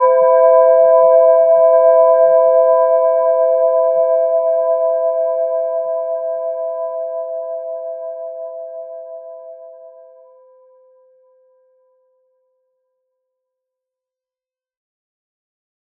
Gentle-Metallic-2-E5-p.wav